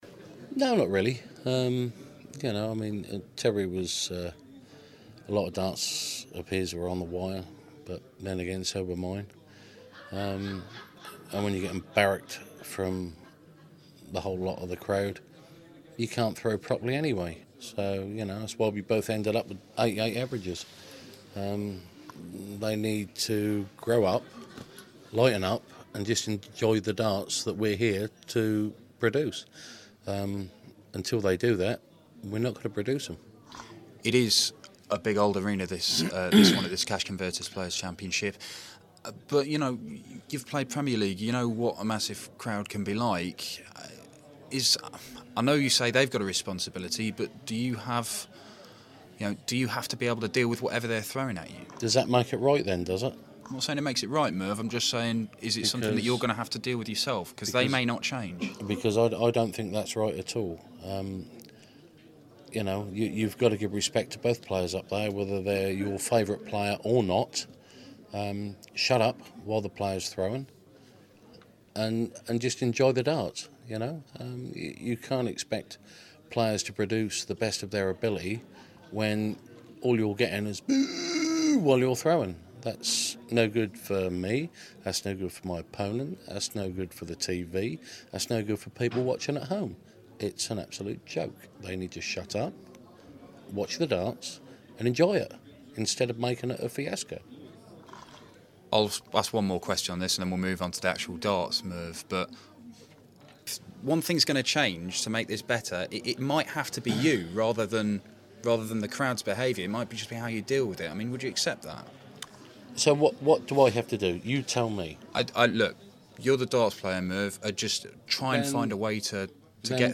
A very angry Mervyn King after his treatment from the crowd during his 6-5 win over Terry Jenkins at the Cash Converters Players Championship.